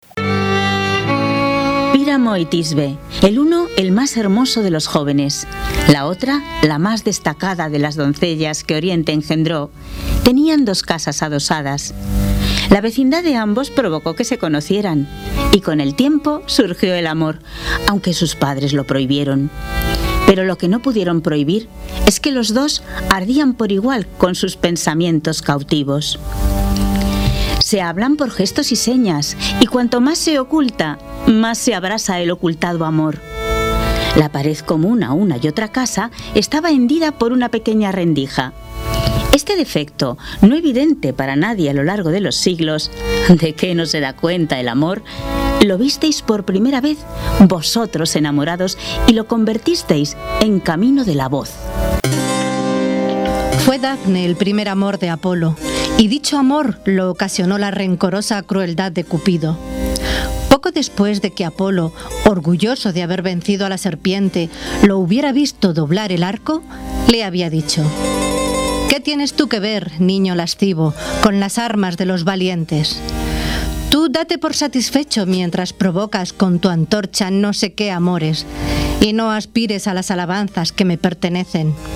Collage con distintos fotogramas del encuentro.Para ello se han realizado dos encuentros online, centrados en diversos aspectos del amor titulados, de forma genérica, ‘El amor en Grecia y Roma’, que han abordado el tema del amor desde diversas perspectivas a través de varios relatos recitados por dos  lectoras profesionales del Servicio Bibliográfico de la ONCE,